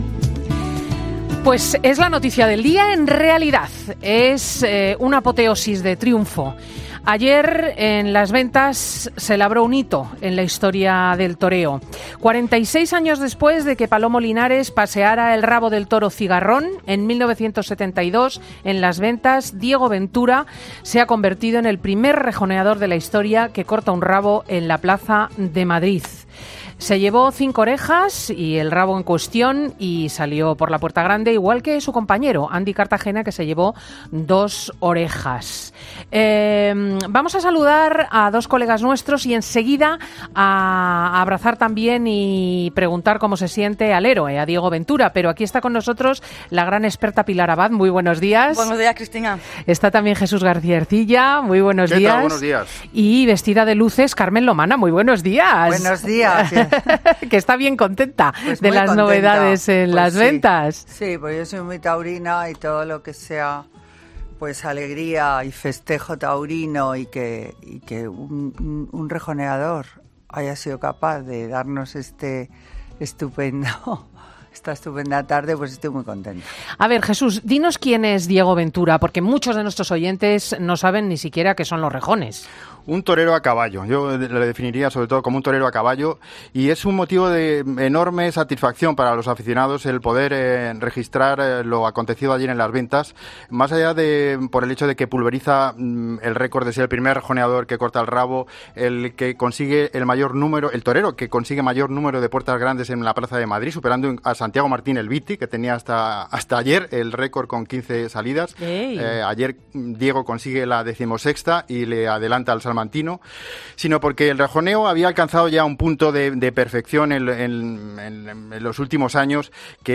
ESCUCHA LA ENTREVISTA COMPLETA A DIEGO VENTURA EN 'FIN DE SEMANA'